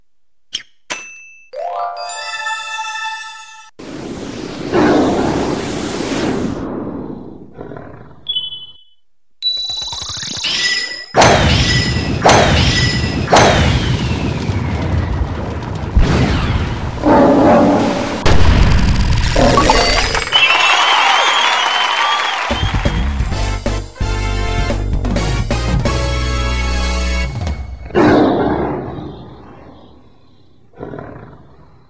lion.mp3